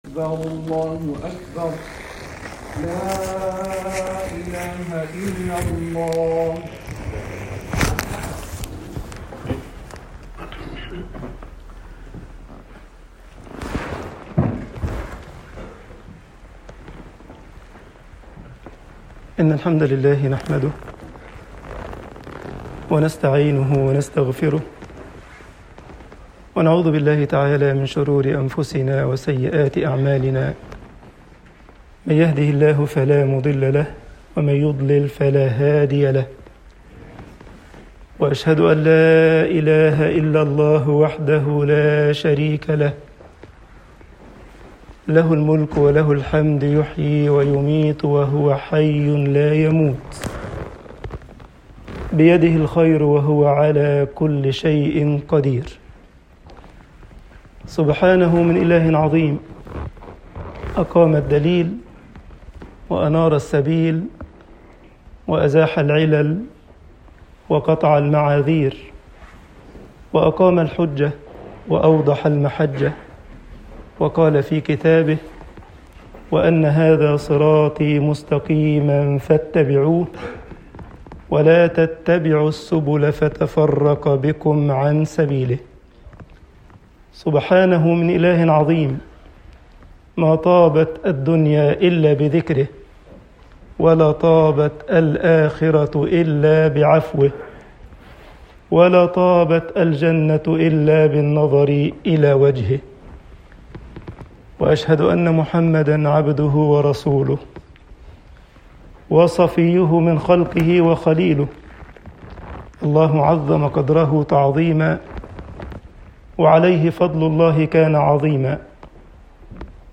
Freitagsgebet_al esmat min fitnat almansib 2.mp3